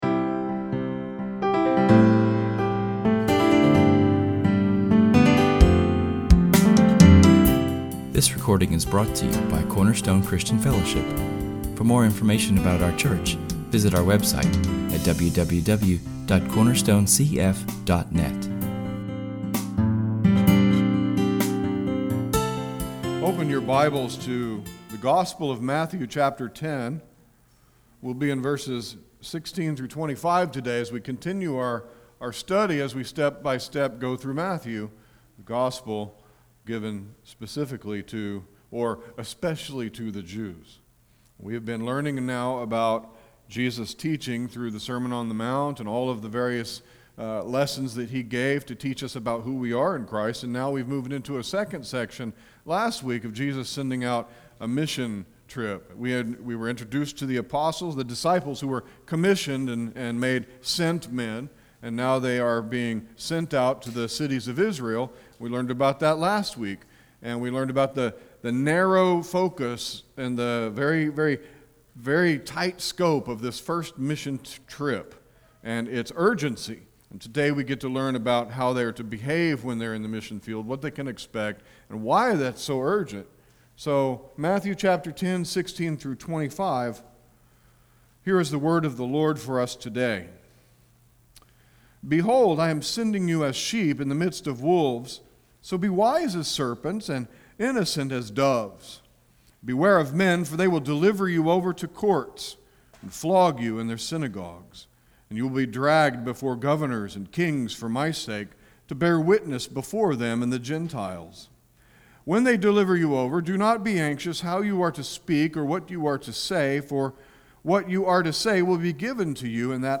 Our sermon continues the Matthew series and we have a sobering subject: How Much is Jesus Worth to You? We will look at [esvignore]Matthew 10:16-23[/esvignore] and see that there are warnings and hardships attached to following Jesus.